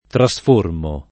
trasformo [ tra S f 1 rmo ]